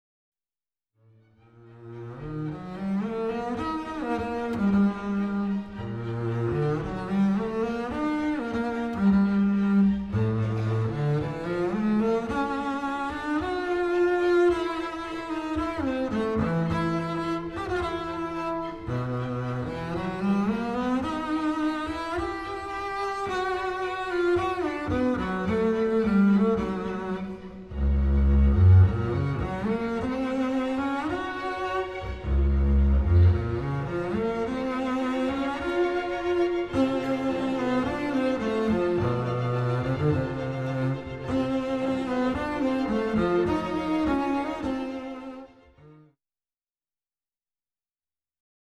Excerpts are from the orchestra version.